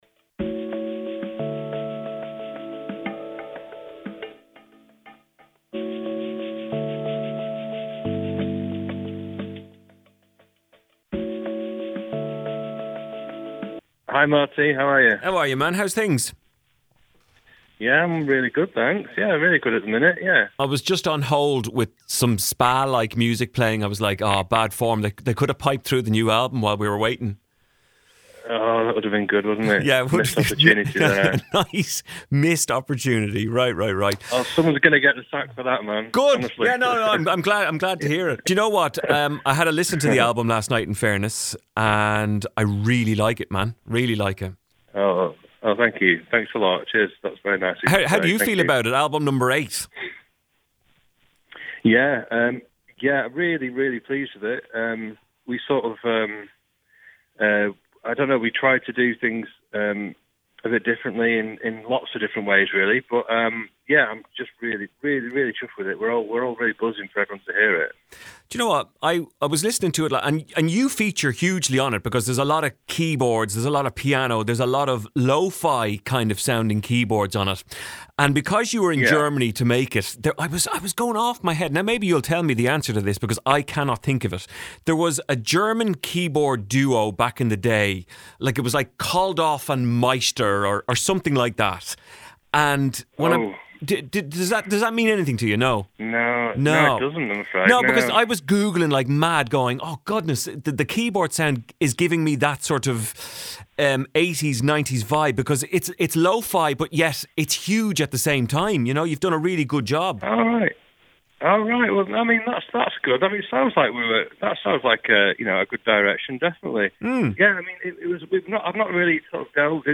Elbow-Craig-Potter-FULL-Chat.mp3